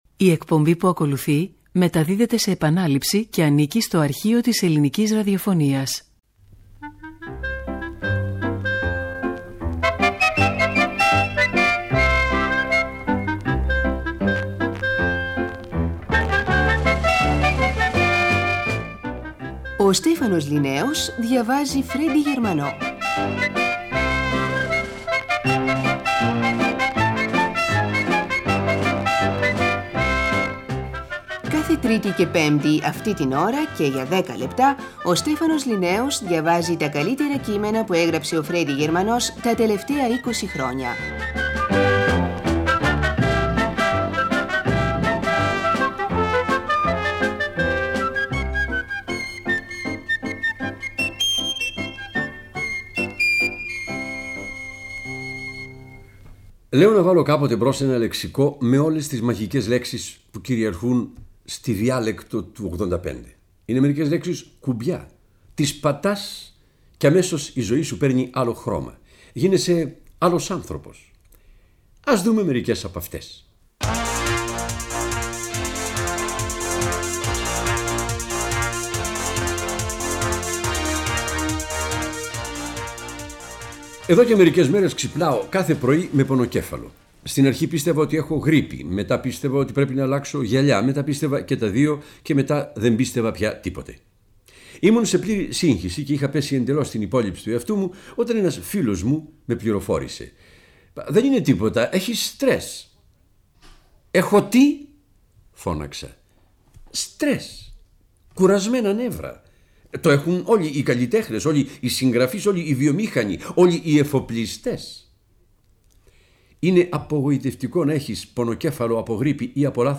Ένα 10λεπτο στο οποίο ο Στέφανος Ληναίος διαβάζει τα καλύτερα κείμενα που έγραψε ο Φρέντυ Γερμανός την εικοσαετία (1965-1985).
Ημερομηνία μετάδοσης: 8/8/1985 – Πρώτο Πρόγραμμα